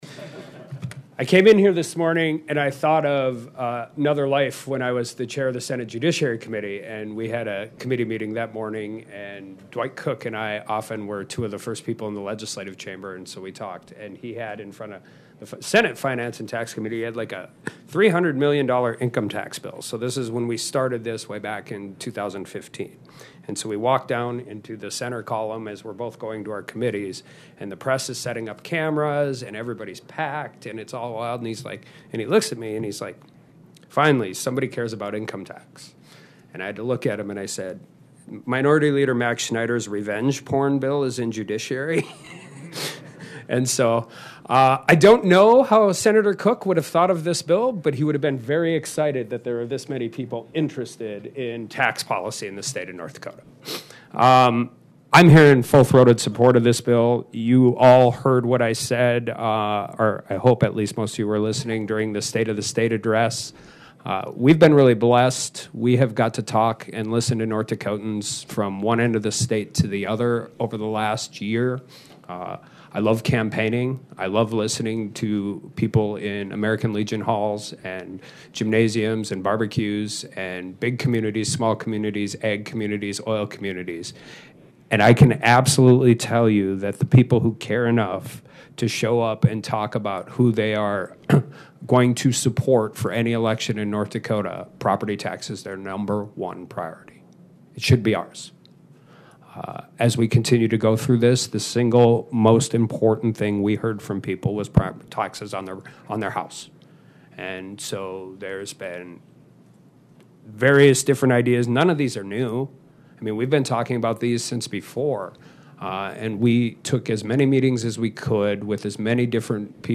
North Dakota Gov. Kelly Armstrong also spoke in favor of HB 1176: